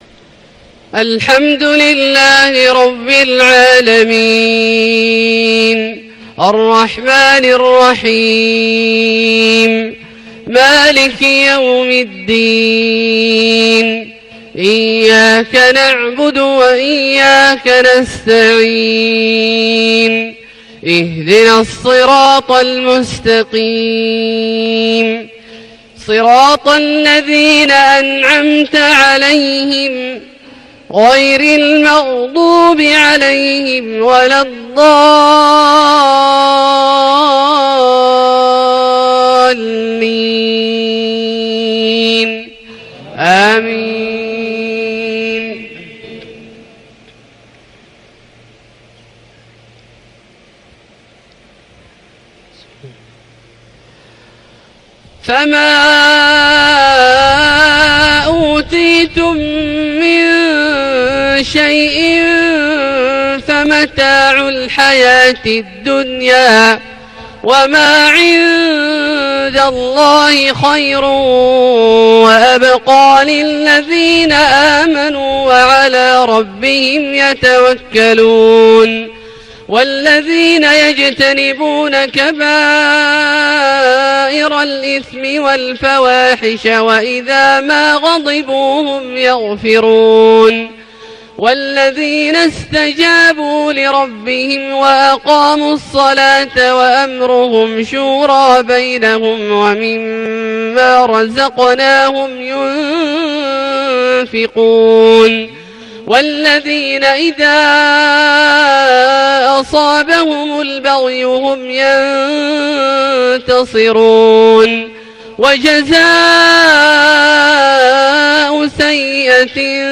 صلاة العشاء 19 رجب 1438هـ للشيخ عبدالله الجهني | تلاوة من سورة الشورى {36-50} > ١٤٣٨ هـ > الفروض - تلاوات عبدالله الجهني